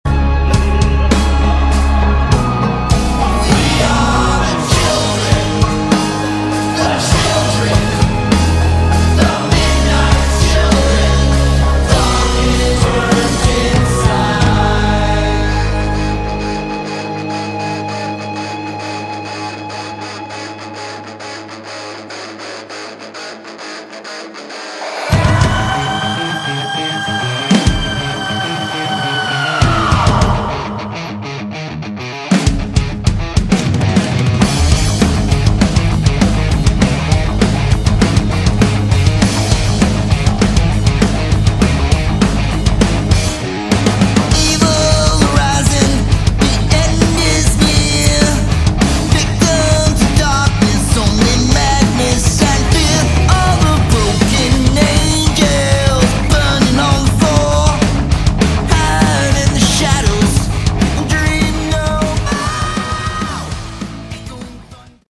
Category: Hard Rock
Guitar
Bass
Drums
Lead Vocals